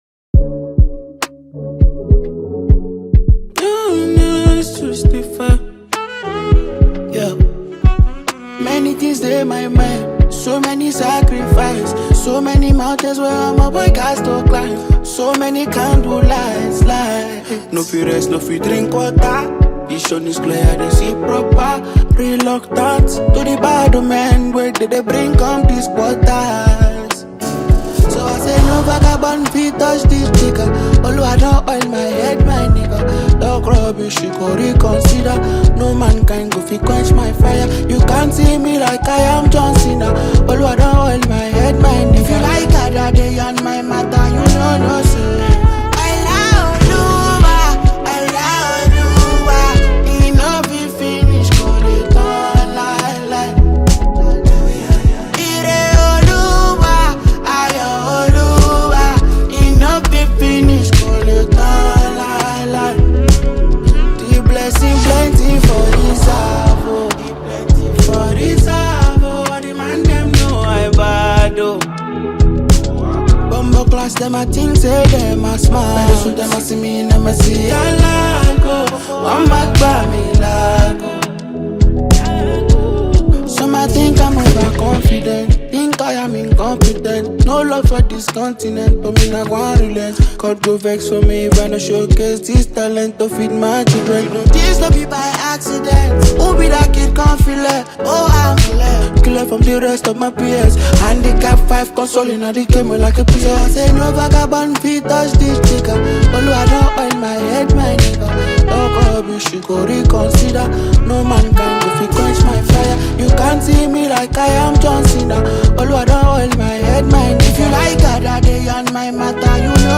blends Afrobeat with Unique sounds
With its upbeat tempo and catchy sounds